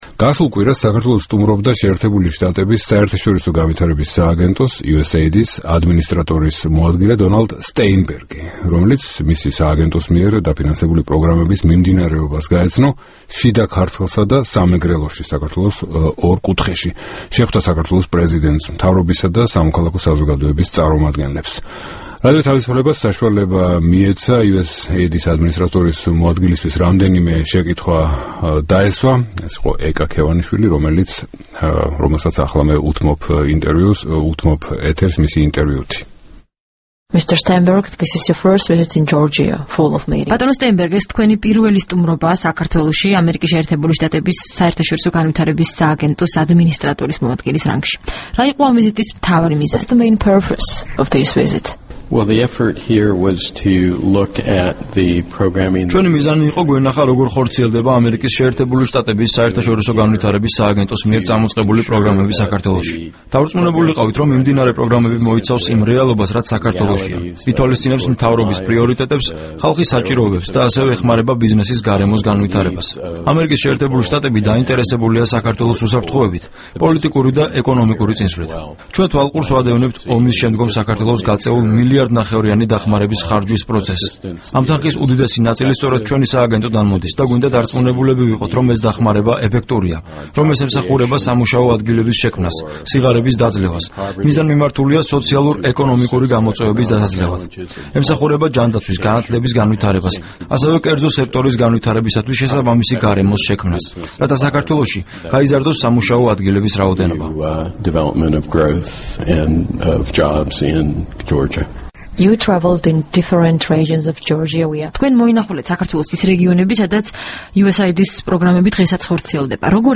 საუბარი დონალდ სტეინბერგთან